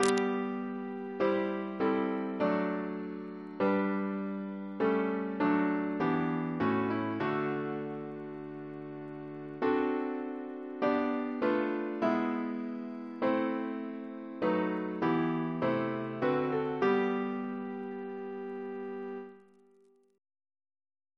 Double chant in G Composer: Percy Buck (1871-1947) Reference psalters: ACB: 232; ACP: 44; RSCM: 26